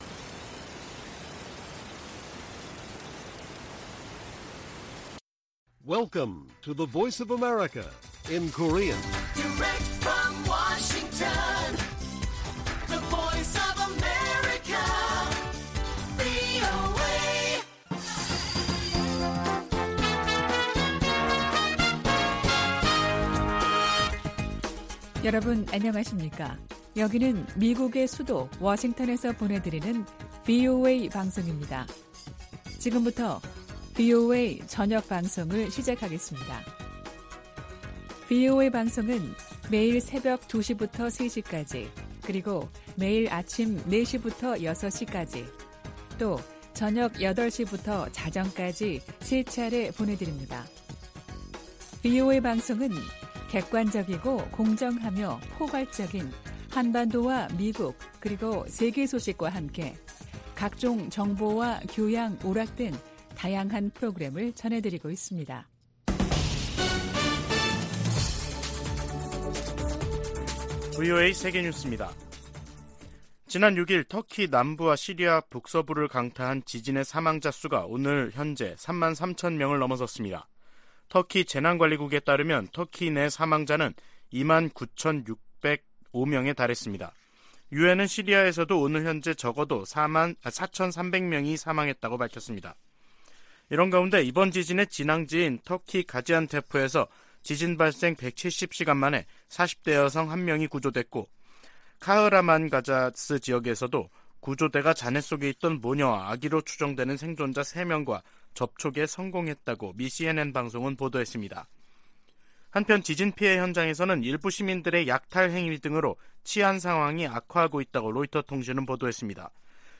VOA 한국어 간판 뉴스 프로그램 '뉴스 투데이', 2023년 2월 13일 1부 방송입니다. 미 국무부는 북한이 고체연료 ICBM을 공개한 것과 관련해, 외교적 관여를 통한 한반도 비핵화 의지에 변함이 없다는 입장을 밝혔습니다. 북한과 러시아 간 군사협력이 한반도에도 좋지 않은 영향을 끼칠 것이라고 백악관이 지적했습니다. 한국의 남북이산가족협회가 북한 측으로부터 이산가족 문제를 토의하자는 초청장을 받았다며 방북을 신청했습니다.